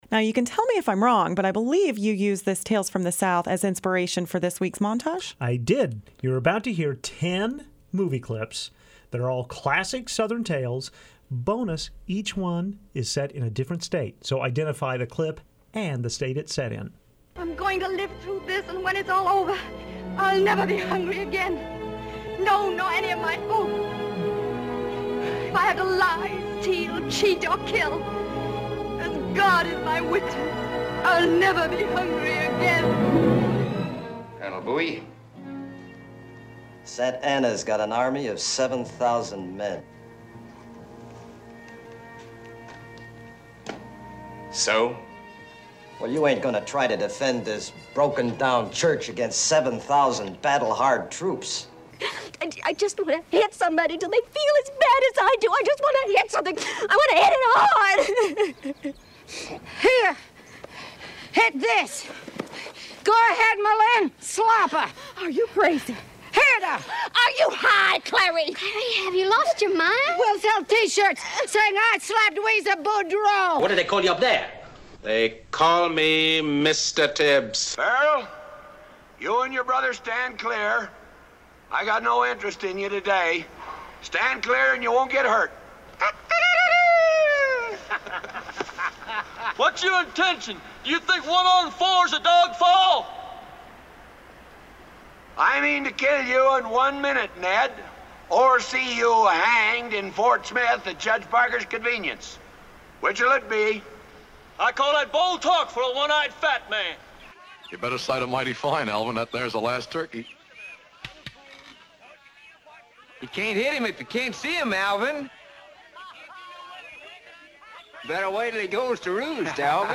Here are our ten clips of ten classic southern tales, each set in a different southern state.
1. Vivian Leigh, as Scarlet, makes a vow at the end of the first half of Gone With the Wind. (Goeriga)
2. Richard Widmark, as Col. Bowie, questions the wisdom of defending the Alamo. (Texas)
7. The five seconds we can air from Al Pacino in Scarface. (Florida)